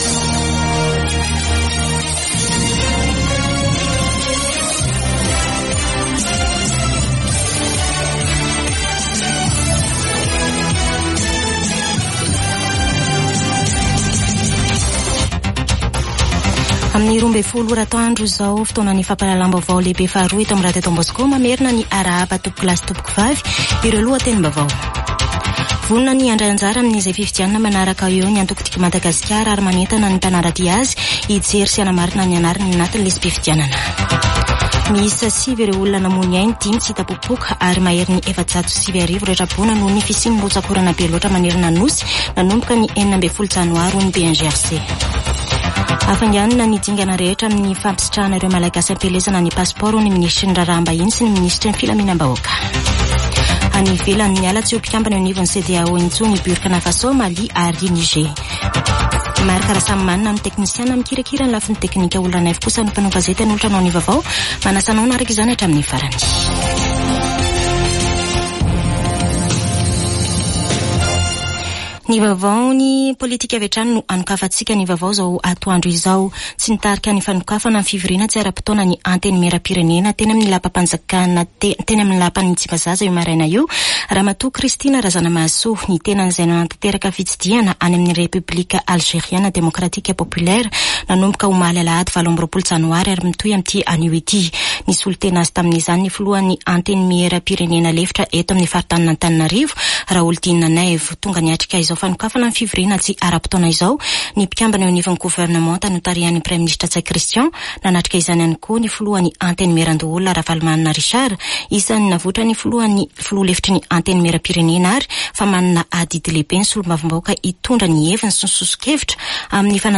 [Vaovao antoandro] Alatsinainy 29 janoary 2024